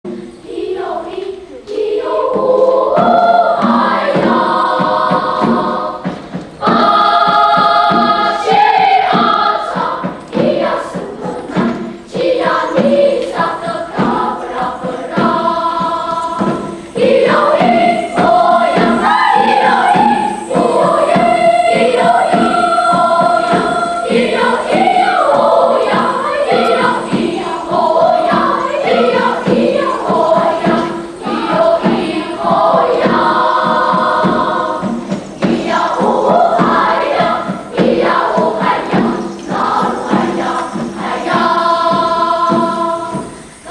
團體歌唱時，仍以領唱與和腔的唱法最為普遍。
排灣族歌唱形式大致有三種：一是兩部不和協之異音唱法。二是具有持續低音之唱法。三是以一個主題作多種變化的變奏唱法。